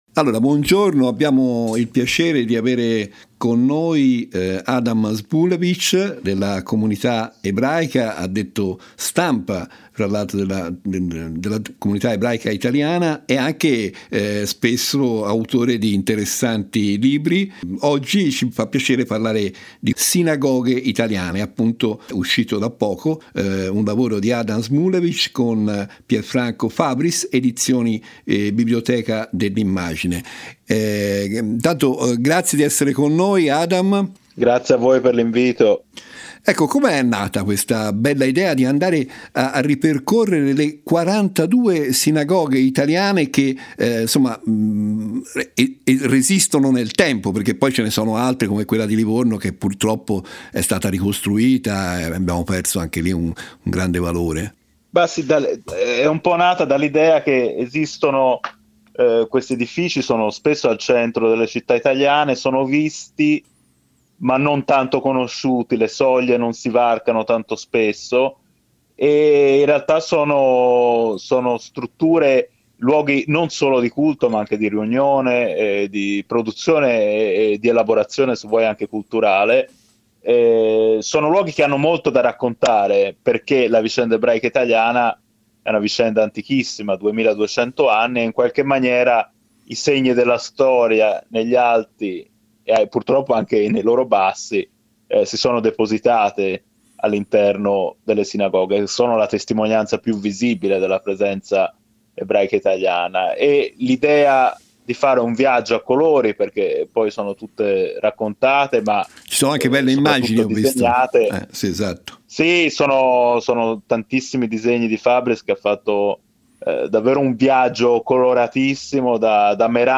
In questo spazio radio dedicato ai libri
intervista